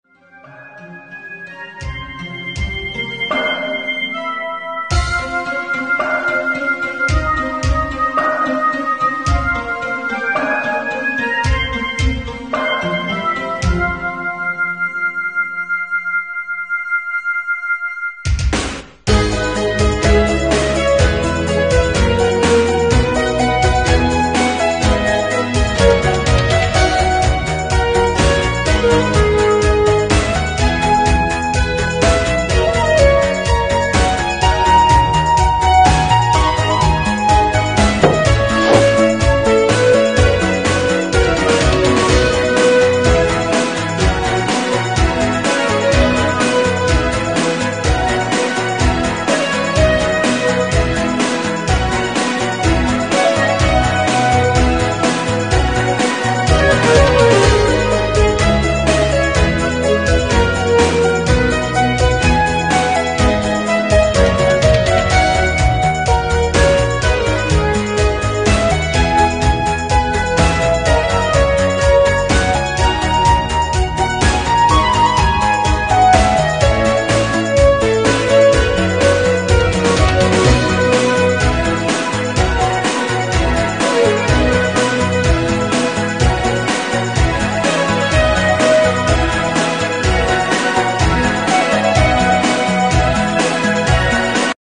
Помогите пожалуйчта опознать инструменталы